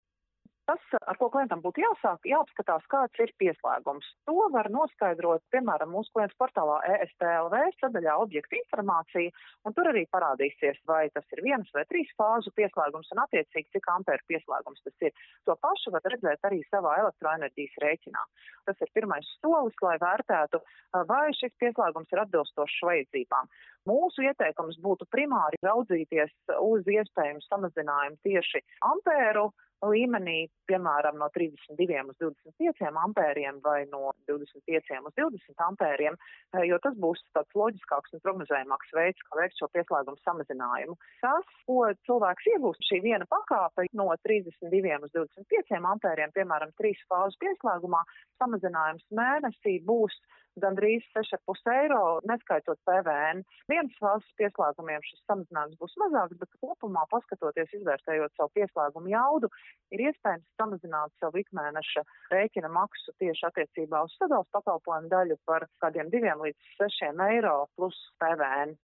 RADIO SKONTO Ziņas par to, kā samazināt elektrības pieslēguma jaudu